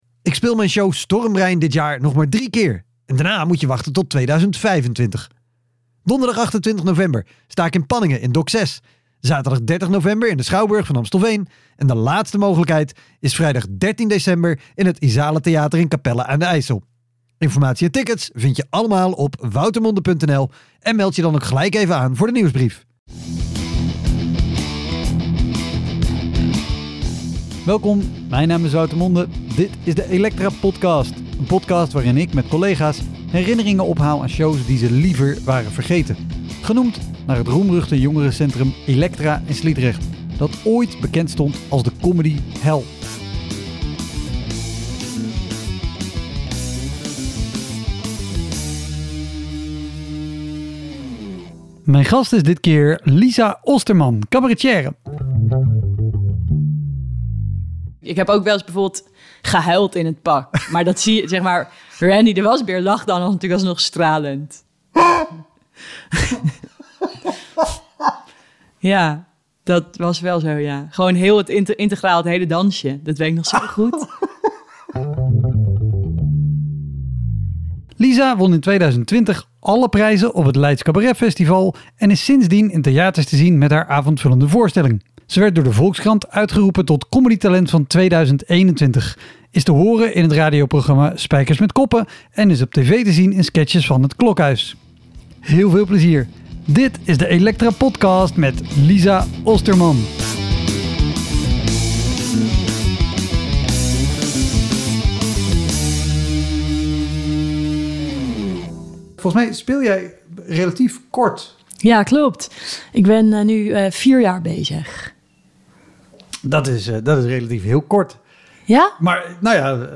Je hoort de grootste grappenmakers over hun aller slechtste optredens en shows.